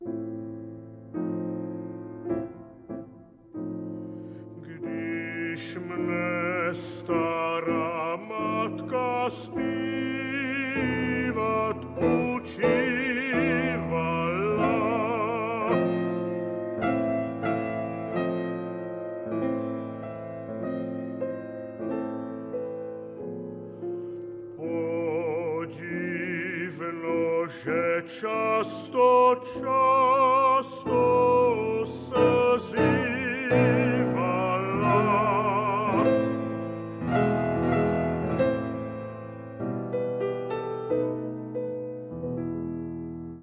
vocal
piano